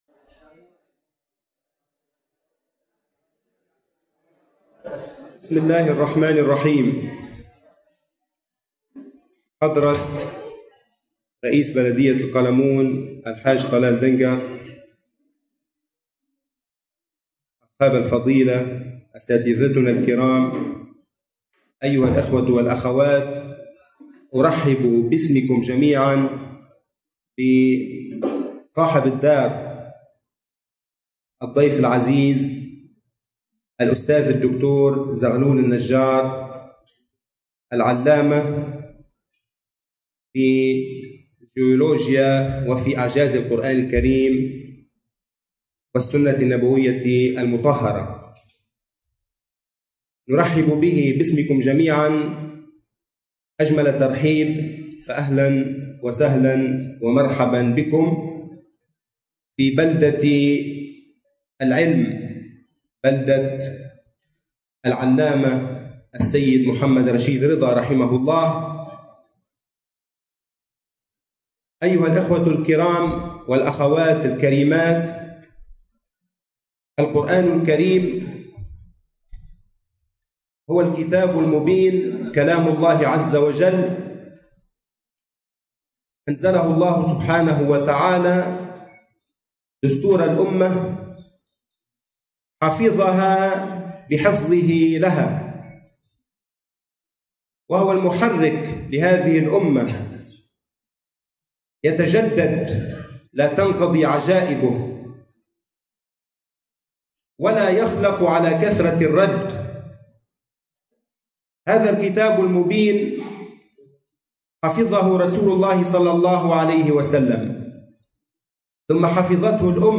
محاضرة الدكتور زغلول النجار بعنوان “إلى القرآن من جديد”
قامت دار الفتوى بالتعاون مع جمعية العزم والسعادة الإجتماعية (القطاع الديني) بتنظيم محاضرة ألقاها فضيلة الدكتور العلامة زغلول النجار حفظه الله تعالى تحت عنوان “إلى القرآن من جديد” اليوم الجمعة الواقع فيه 24 أيار 2013 م الموافق له 14 رجب 1434 هـ في قاعة العزم للمناسبات.